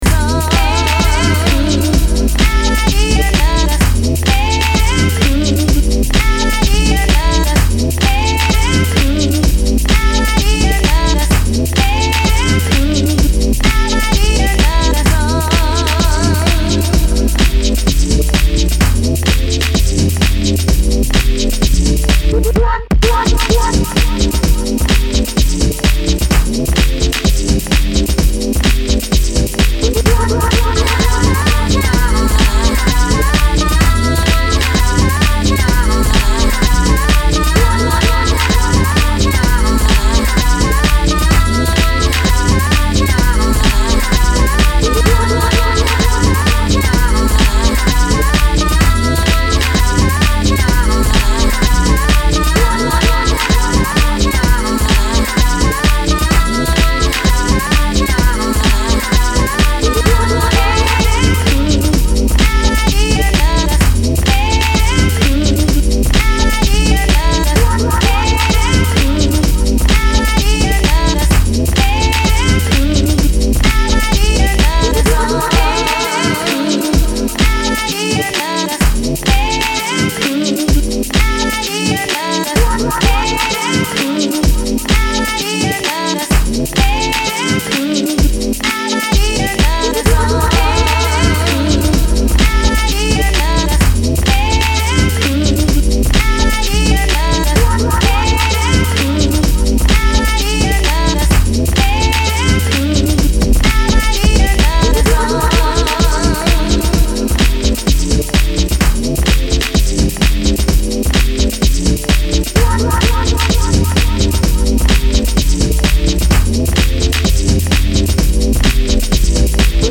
ファンキーなディープ・ハウスに仕上げています！